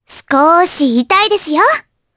Mirianne-Vox_Skill3_jp.wav